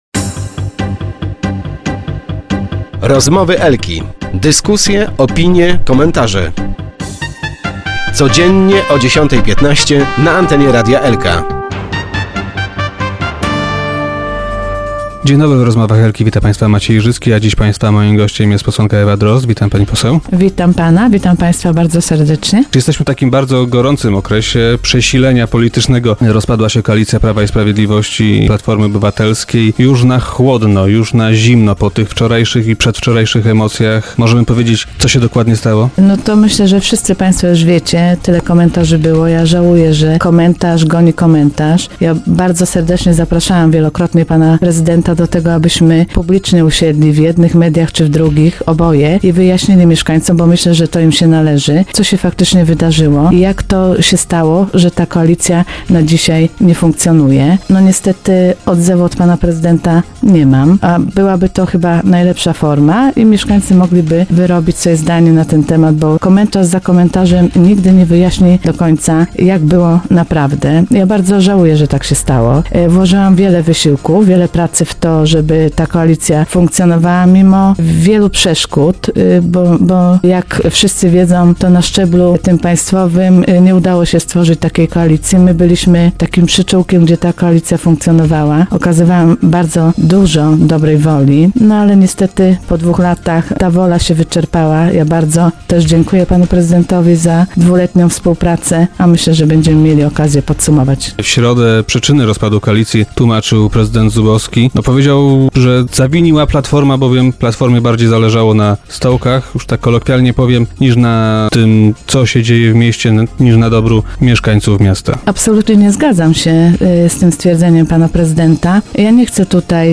Jak powiedziała Ewa Drozd, gość dzisiejszych Rozmów Elki, Platforma nie była przygotowana na taką ewentualność.